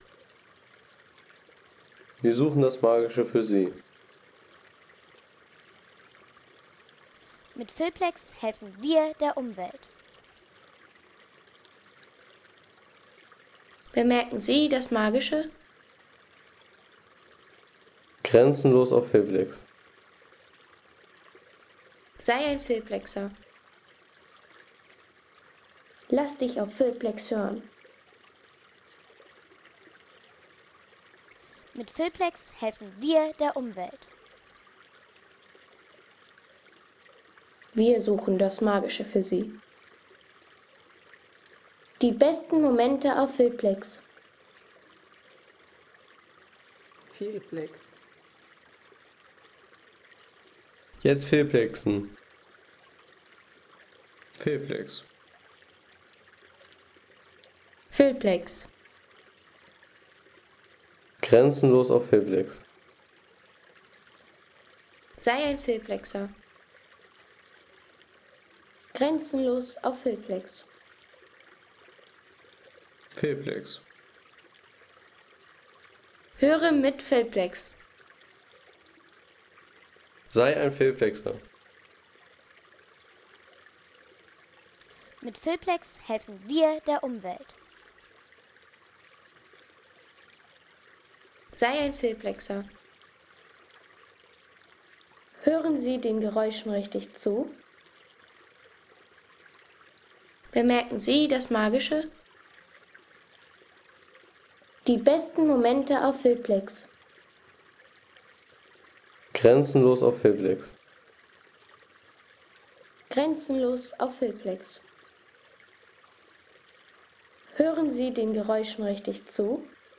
Der Wasserbach Amselgrundbach | Friedliche Naturklangkulisse
Authentische Bachatmosphäre vom Amselgrundbach in Deutschland mit sanftem Wasserrauschen und Vogelstimmen.
Ein ruhiger Bach-Sound aus Deutschland mit sanftem Wasserfluss und Vogelstimmen für Filme, Reisevideos, Dokus und Sound-Postkarten.